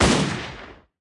Media:Shelly_base_atk_1.wav 攻击音效 atk 初级和经典及以上形态攻击音效